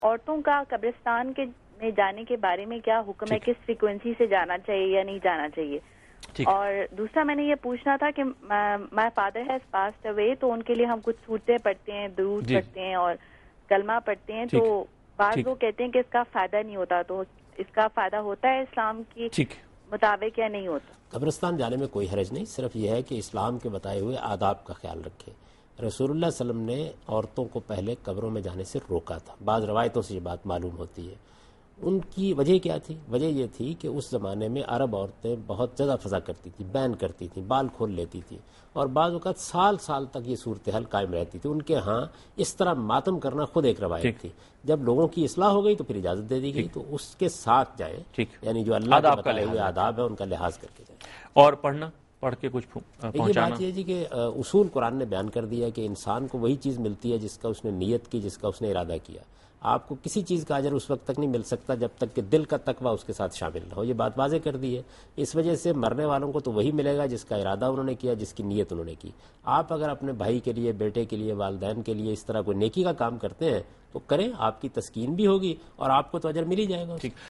TV Programs
Answer to a Question by Javed Ahmad Ghamidi during a talk show "Deen o Danish" on Duny News TV